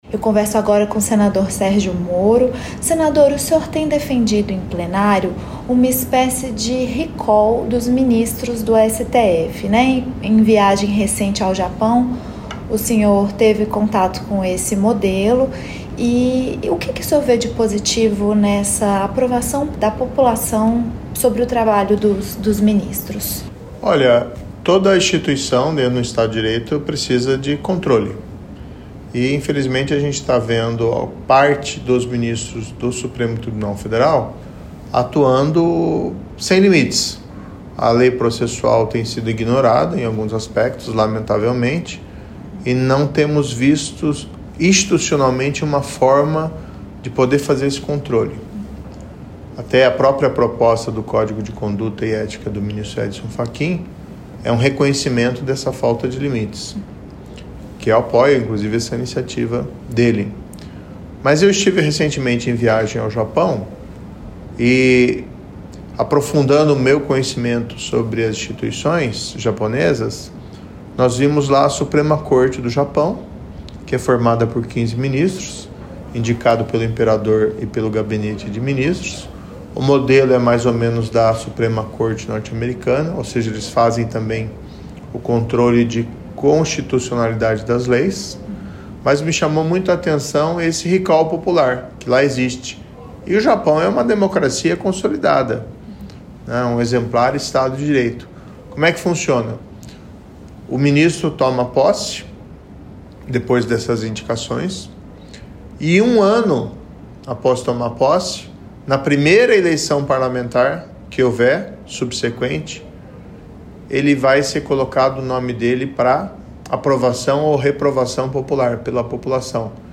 Entrevista com o senador Sérgio Moro sobre a possibilidade de uma PEC prevendo o recall de ministros do STF